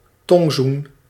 Ääntäminen
IPA: [pa.tɛ̃]